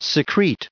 added pronounciation and merriam webster audio
1542_secrete.ogg